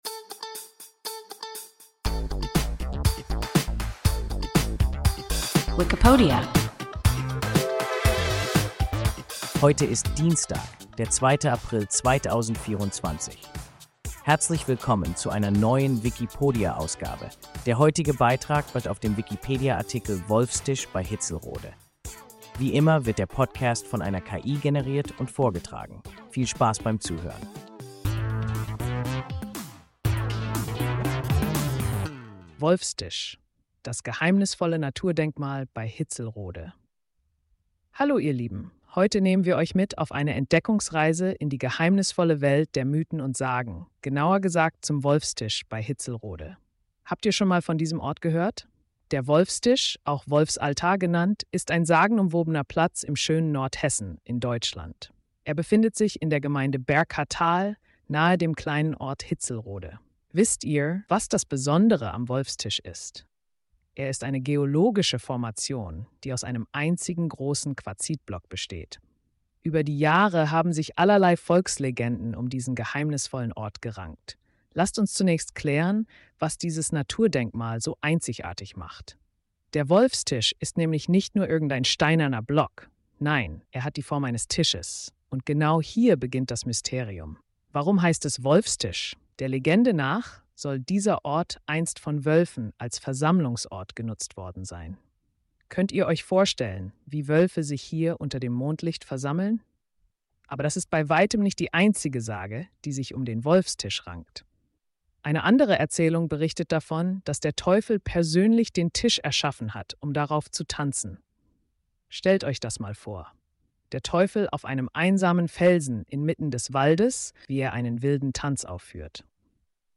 Wolfstisch bei Hitzelrode – WIKIPODIA – ein KI Podcast